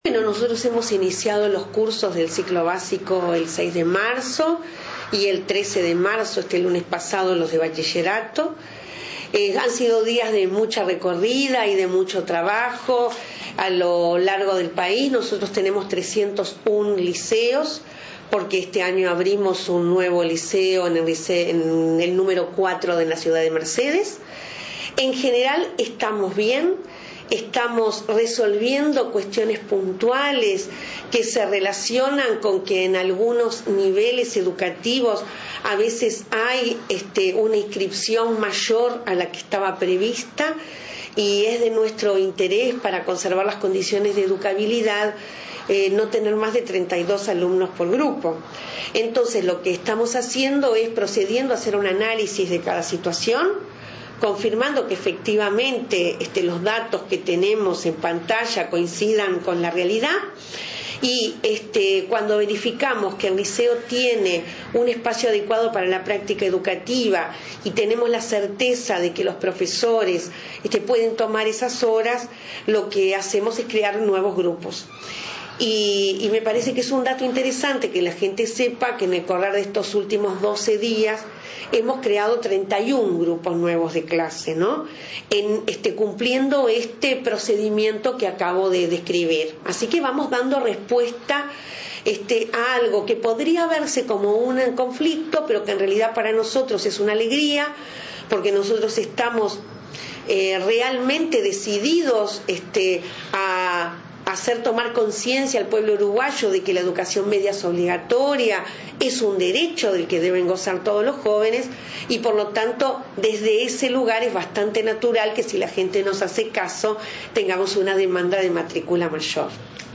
En diálogo con la Secretaría de Comunicación, hizo un repaso del comienzo de clases y habló de dificultades puntuales en algunos centros por reparaciones edilicias.